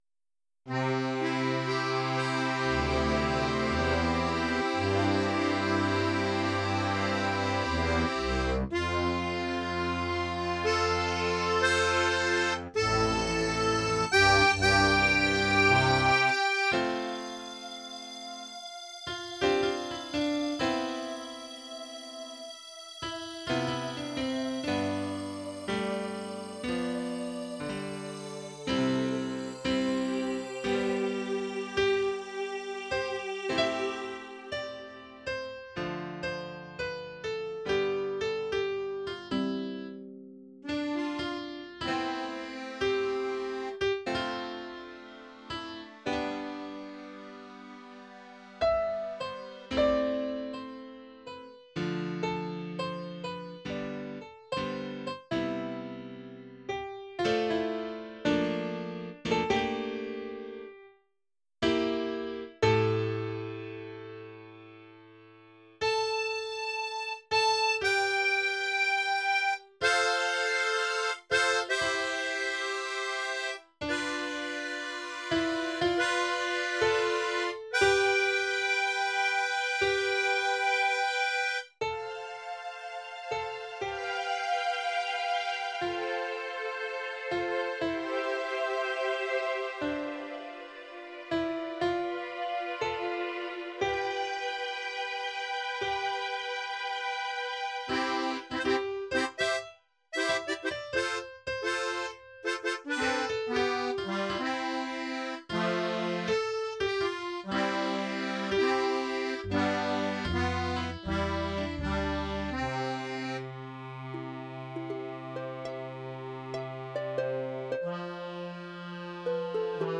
Instrumentierung/Stimmen: Klavier, Klavier 2, WhySynthIceChorus, slo strings Akkordeon, Orgel Mitte, Harfe, Acc Bass, ah-Chor
C-Dur.
Eine ernste Unterhaltung der Engel wird vom Akkordeon eingeleitet und von 2 Klavieren und dem IceChorus dargestellt. Die sich anschliessende "Fachdiskussion" ist vom Duktus her ebenfalls sehr nachdenklich, wie bei Engeln erwartet.